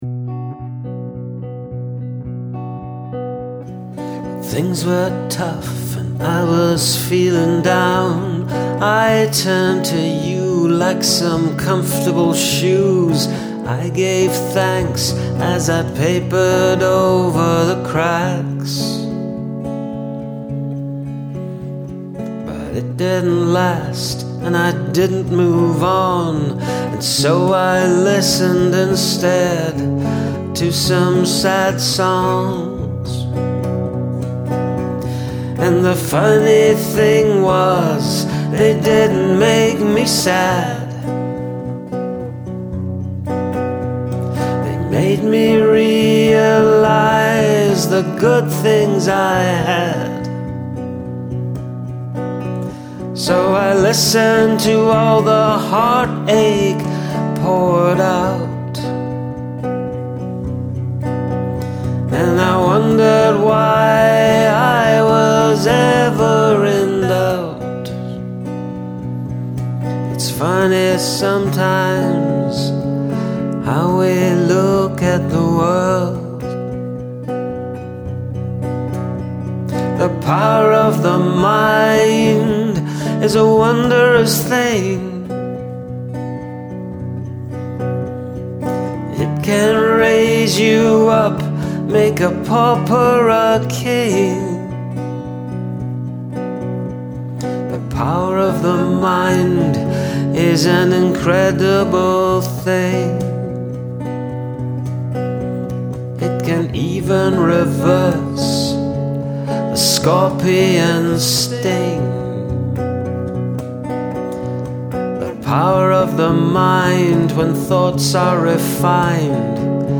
Great harmonies on this one.
Like the harmony.